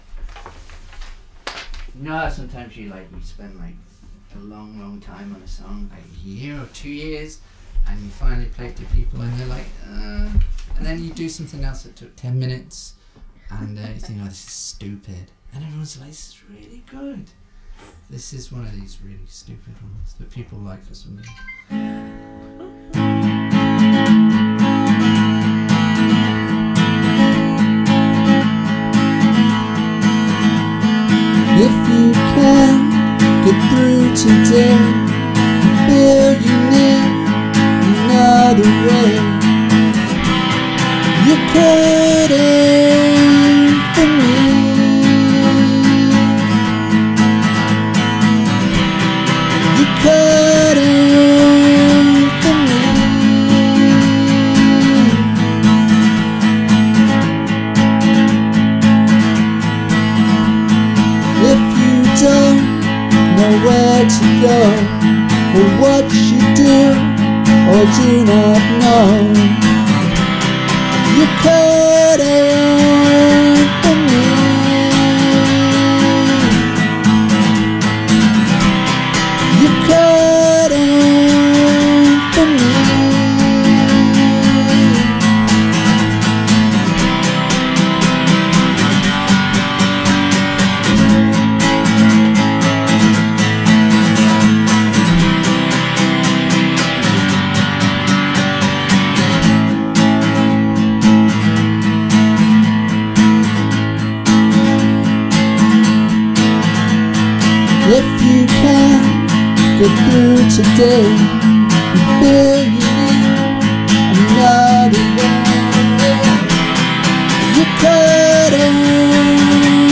Indie-Rock > Singer-Songwriter > Psych-Folk